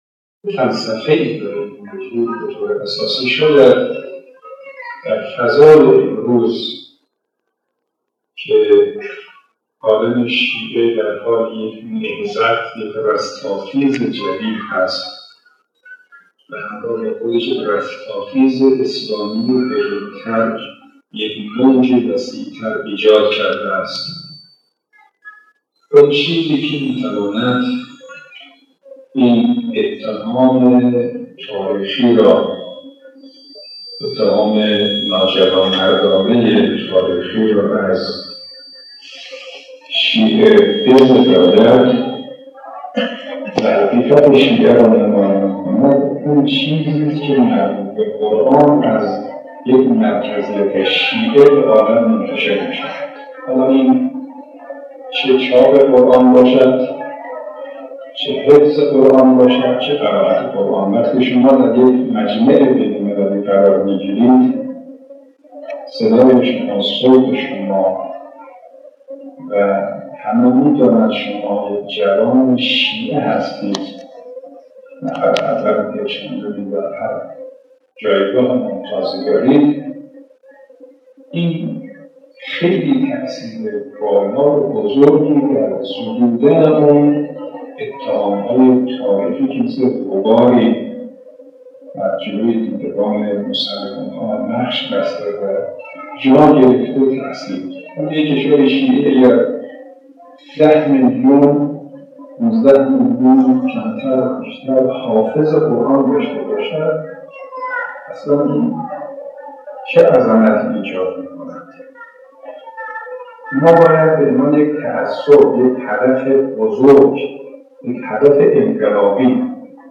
مشروح سخنرانی سردار قرآنی، شهید حاج قاسم سلیمانی در یک محفل قرآنی درباره اهمیت و جایگاه قرائت و تلاوت برای نخستین‌بار منتشر می‌شود.
این سخنان در شب 22 بهمن‌ماه 1397 در بیت‌‌الزهرای کرمان ایراد شده است: